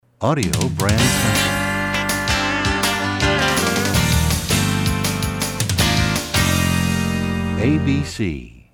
MCM Category: Radio Jingles
Genre: Jingles.